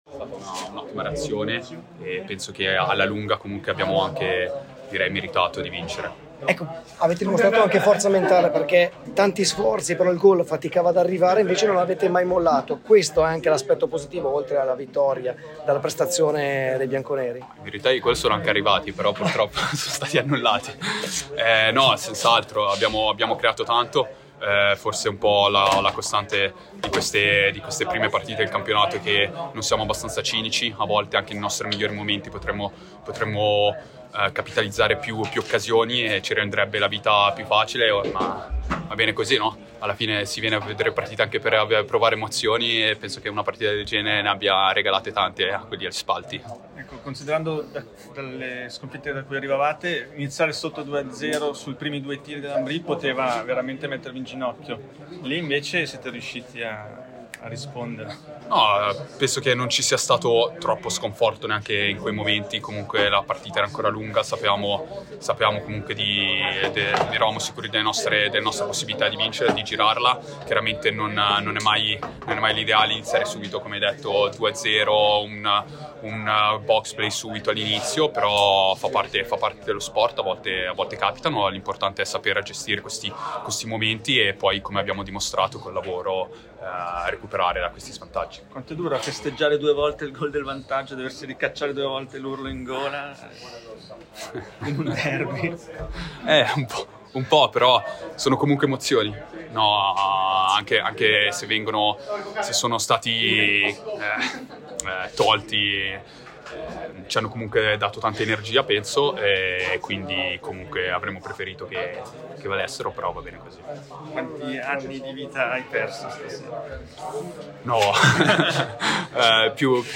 Interviste: